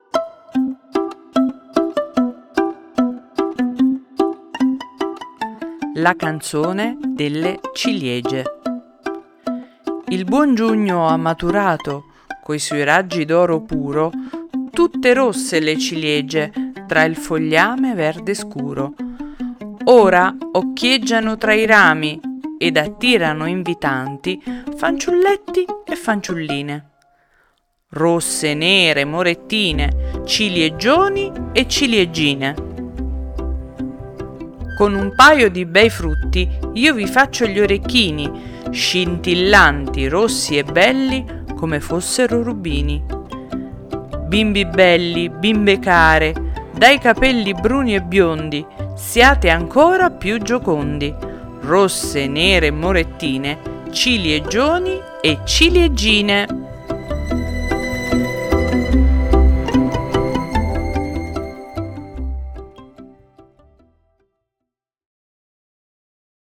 mamma legge la fiaba